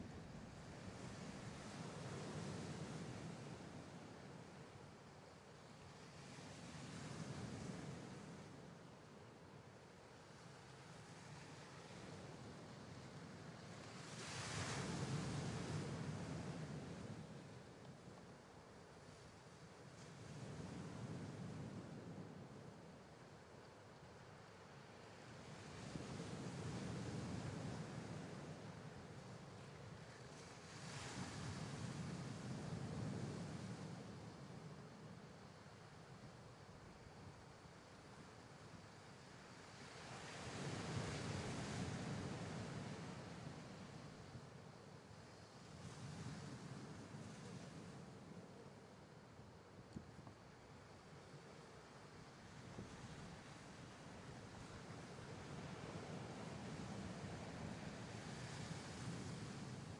Ambient sounds » Sea Shore calm waves
描述：Recorded stereo within a Moto G cellphone.
标签： boat ocean sea shore rio wave de janeiro Brasil
声道立体声